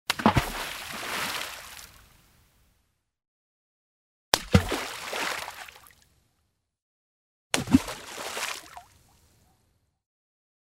На этой странице собраны натуральные звуки шагов по лужам: от легкого шлепанья до энергичного хлюпанья.
Звук камня падающего в большую лужу воды